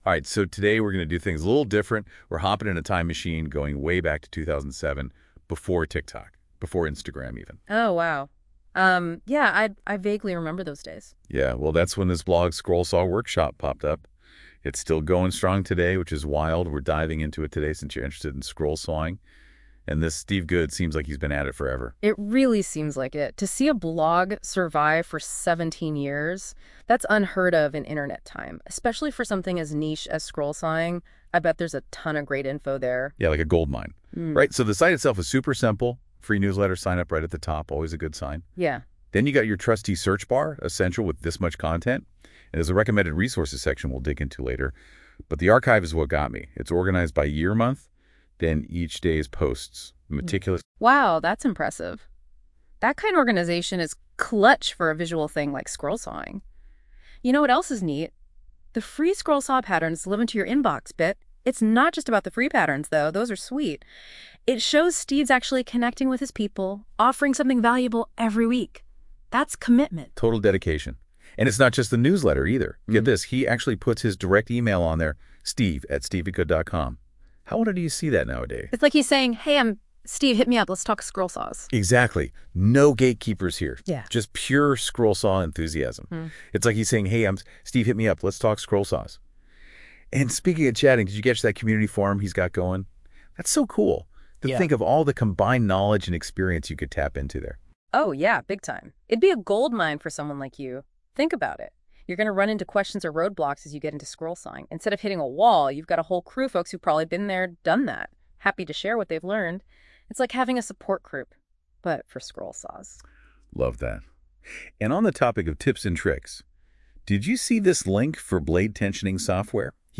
This audio podcast about my blog was generated completely by AI. All I did was give it the link to my blog and ask it to generate a two-person audio podcast.
It researched my blog and generated a 5-minute conversation that is so realistic it is difficult to believe.